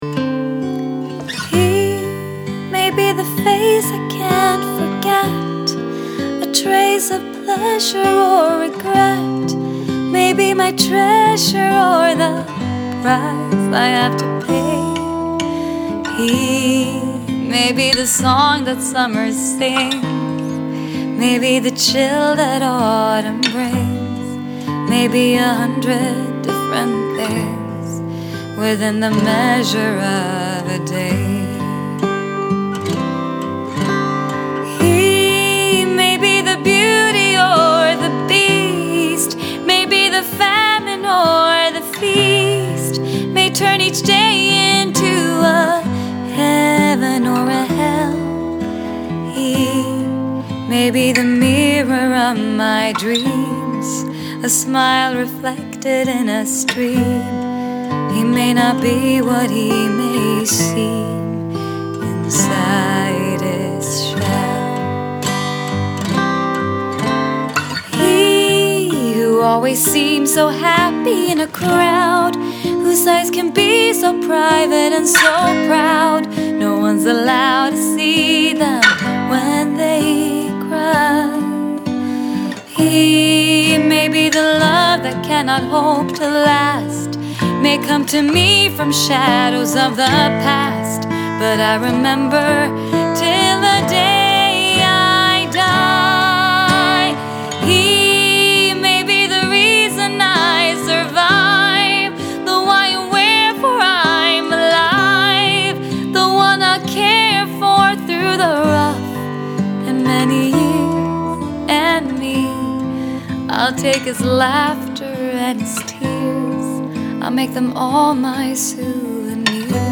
Vocalist // Composer
Acoustic version